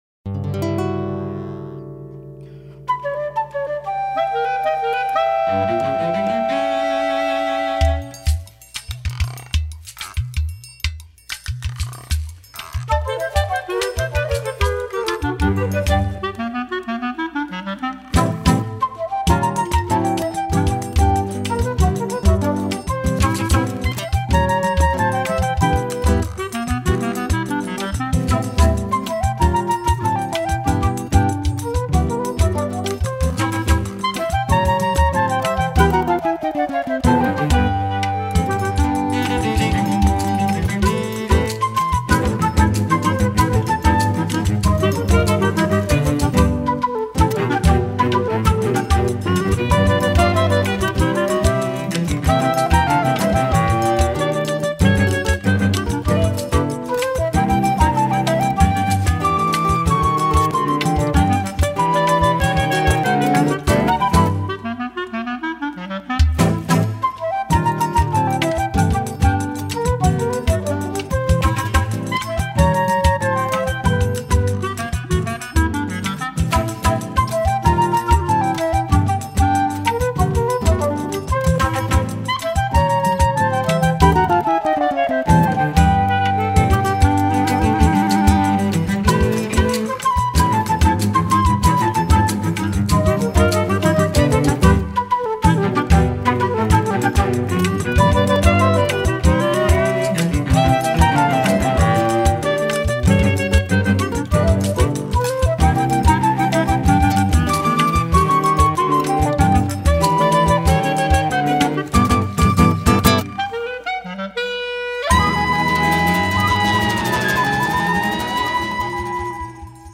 2027   02:03:00   Faixa:     Instrumental